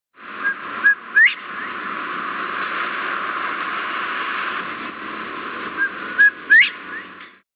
This Spot-bellied Bobwhite (Colinus leucopogon) was recorded in a vacant field across from the Hampton Inn parking lot in Alahuela (Costa Rica), 6/17/99. He was sitting in the top of a small shrub right in the middle of the traffic & hubbub of the city!